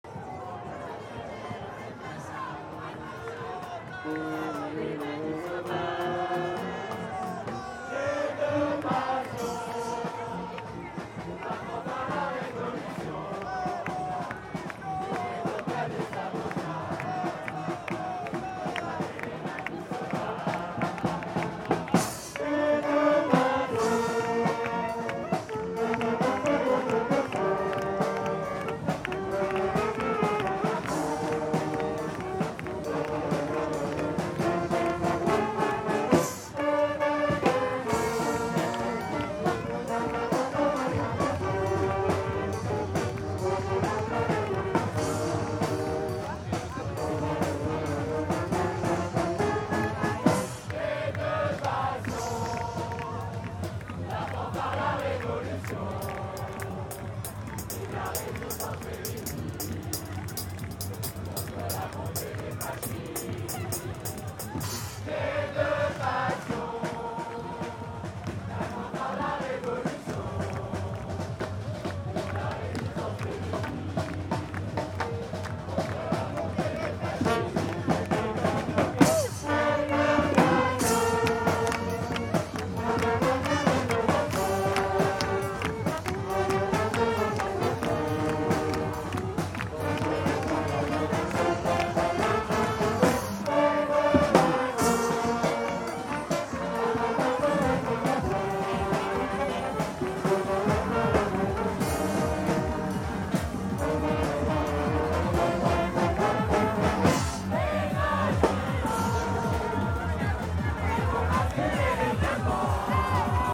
Demonstration in Paris. It involves singing with a marching band.